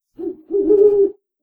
owlsfx.11e53683.wav